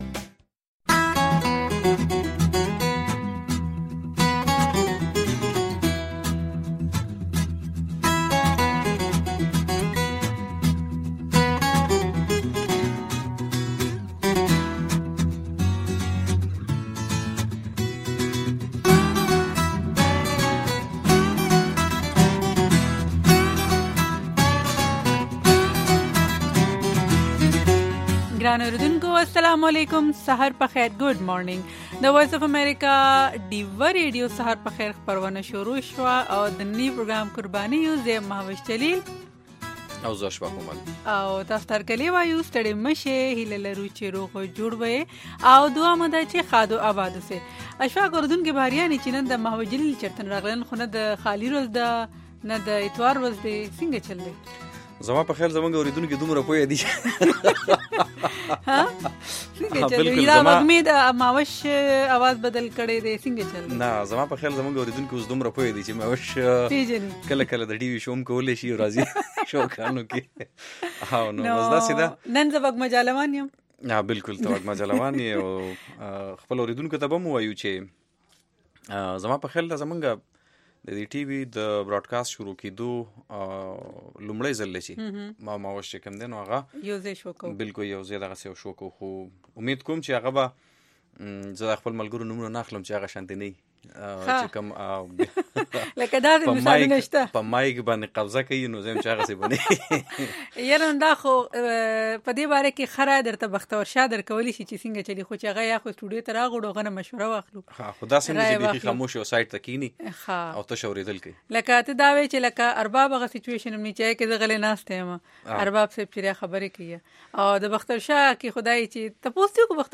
په دې دوؤ ساعتو پروگرام کې تاسو خبرونه او د هغې وروسته، په یو شمېر نړیوالو او سیمه ایزو موضوگانو د میلمنو نه پوښتنې کولی شۍ.